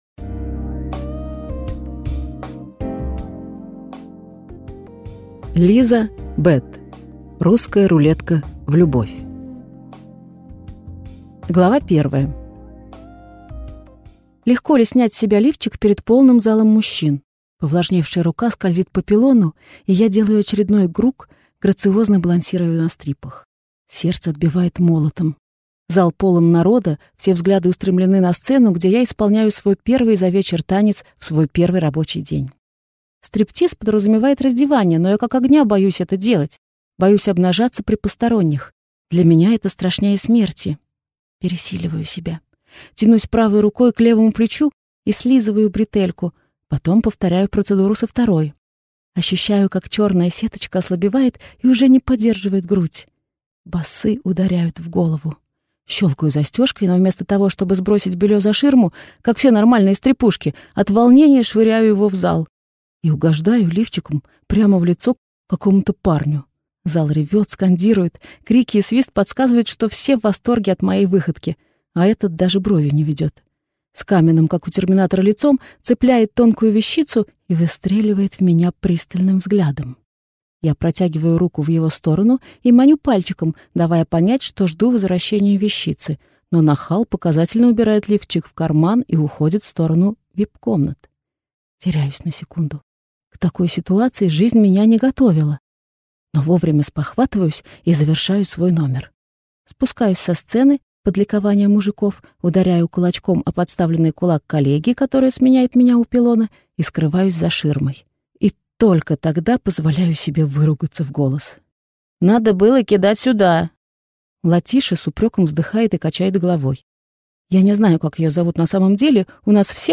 Аудиокнига Русская рулетка в любовь | Библиотека аудиокниг